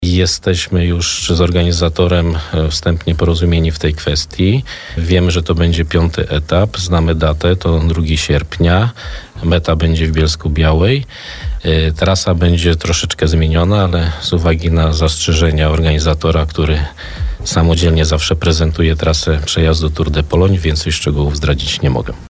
W stolicy Podbeskidzia ulokowana będzie meta jednego z etapów Tour de Pologne. W porannej rozmowie na naszej antenie potwierdził to zastępca prezydenta Bielska-Białej.